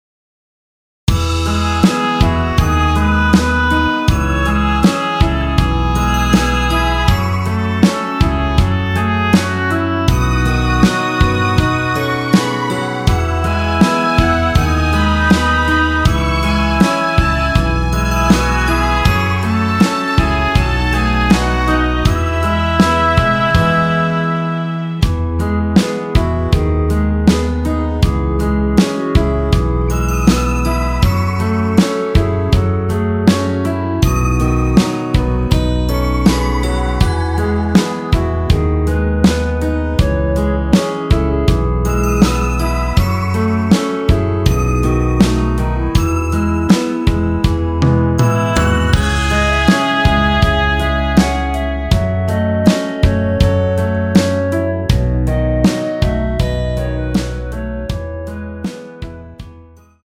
원키 멜로디 포함된 MR입니다.
멜로디 MR이란
앞부분30초, 뒷부분30초씩 편집해서 올려 드리고 있습니다.
중간에 음이 끈어지고 다시 나오는 이유는